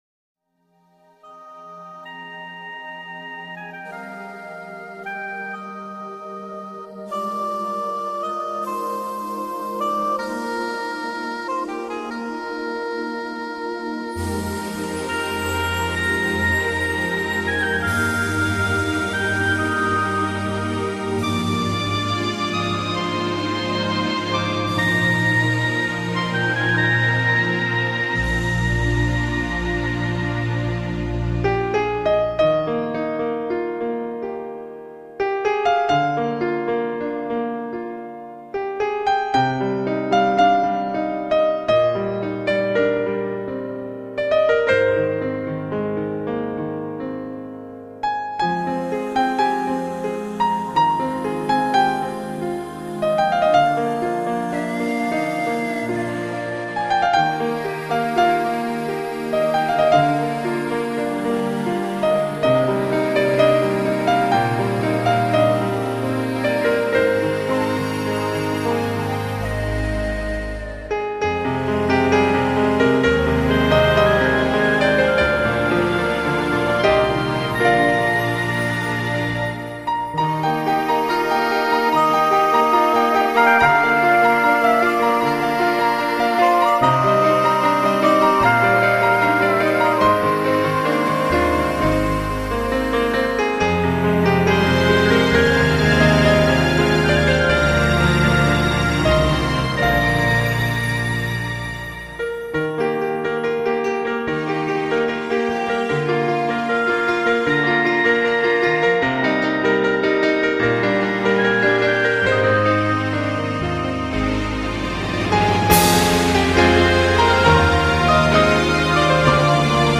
【钢琴曲】
音乐风格：New Age